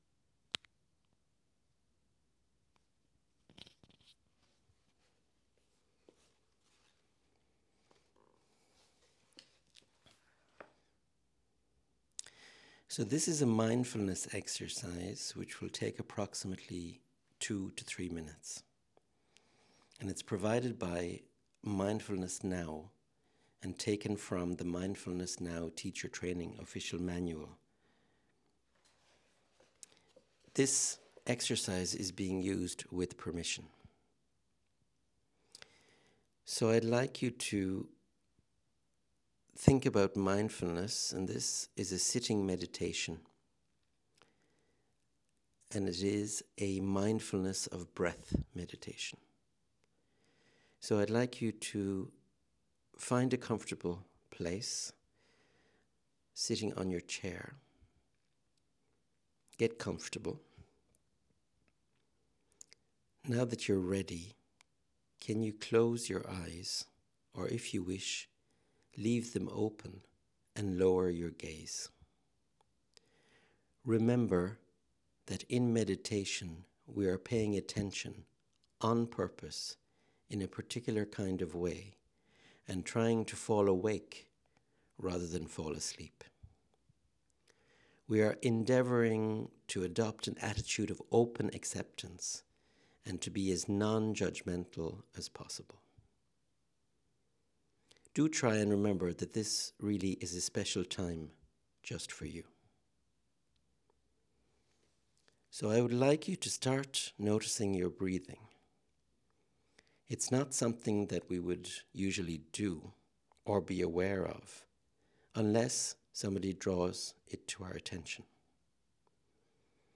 (1) Enregistrement vocal de 5 minutes environ disponible ci-dessus